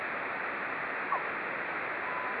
Meteo Scatter - Radar Graves - Meteore Vhf
Esperimenti di meteorscatter con il radar Vhf Graves